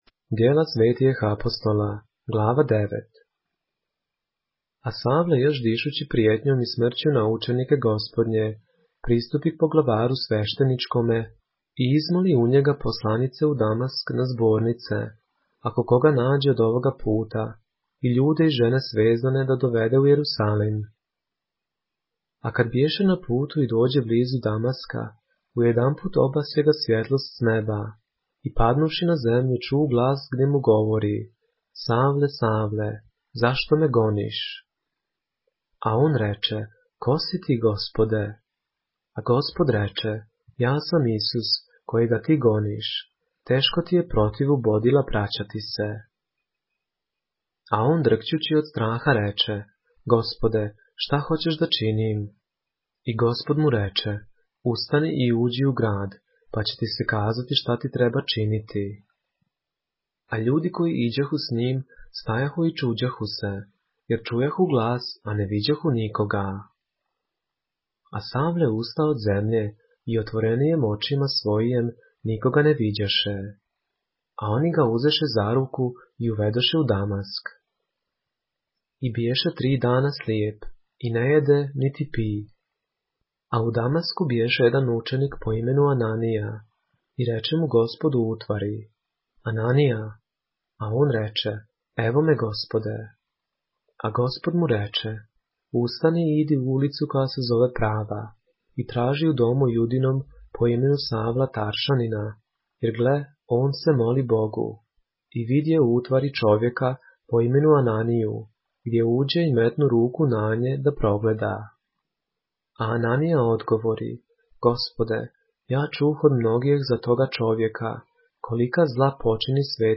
поглавље српске Библије - са аудио нарације - Acts, chapter 9 of the Holy Bible in the Serbian language